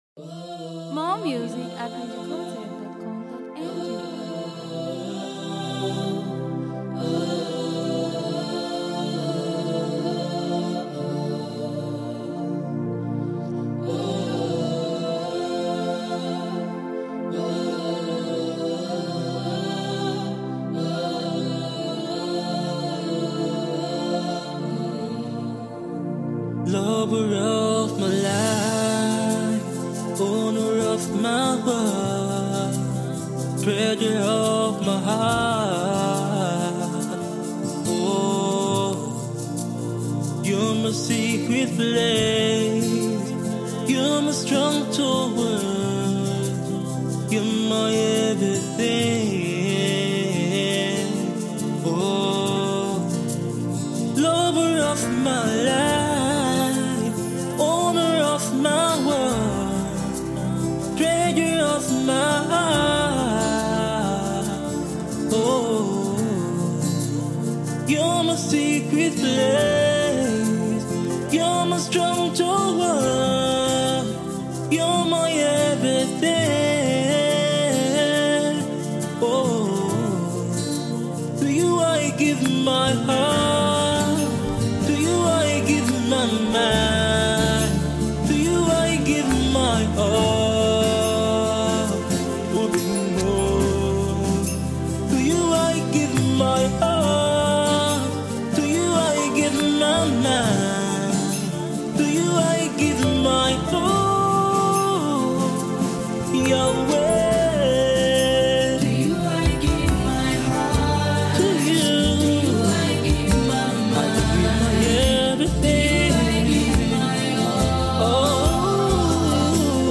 This is a pretty worship song from a Nigerian Gospel singer